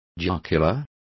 Complete with pronunciation of the translation of jocular.